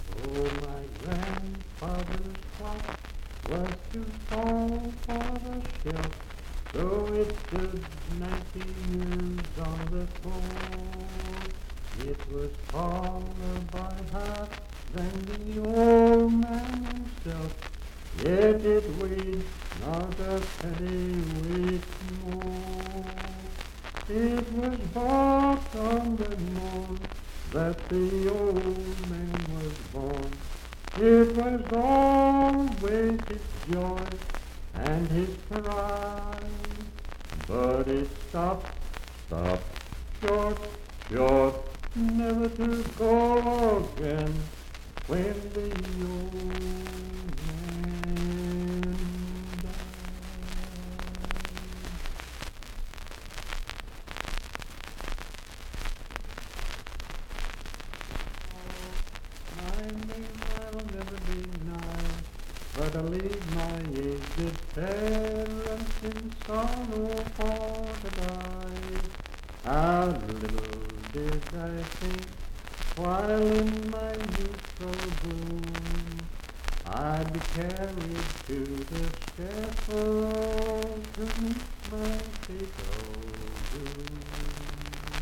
Unaccompanied vocal music
Verse-refrain 1(8).
Miscellaneous--Musical
Voice (sung)